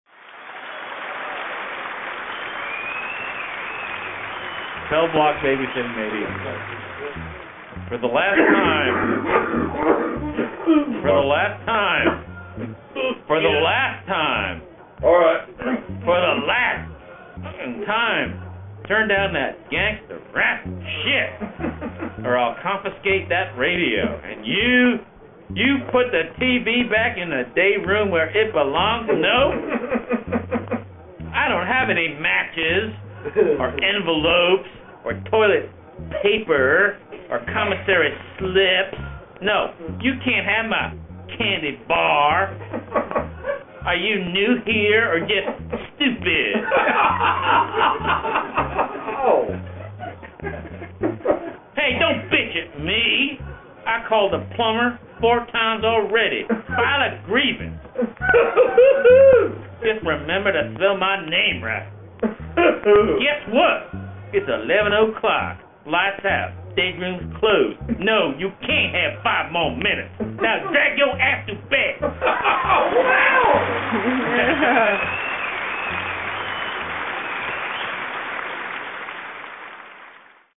These live recording were made 3/13/02
in Hendersonville, outside of Nashville.